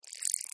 Блоха шевелит лапочками